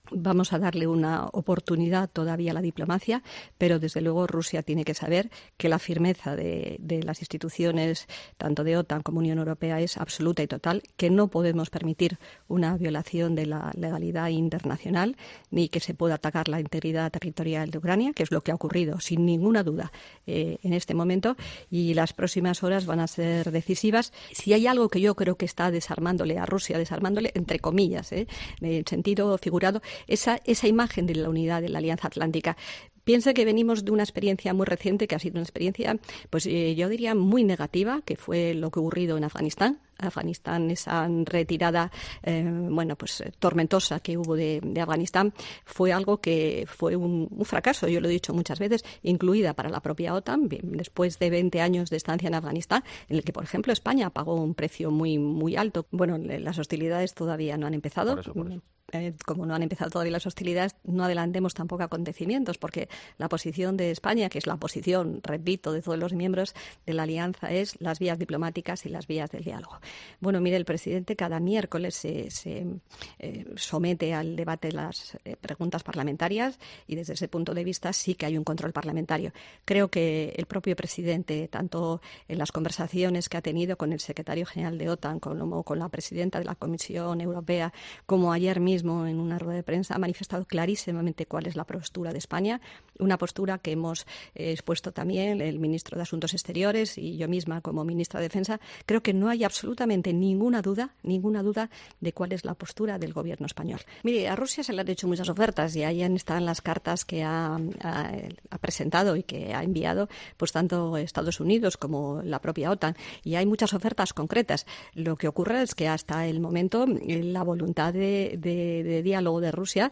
La entrevista en COPE a Margarita Robles, en cinco frases
Tras los últimos acontecimientos del conflicto de Ucrania, la Ministra de Defensa, Margarita Robles, ha pasado por los micrófonos de Herrera en COPE.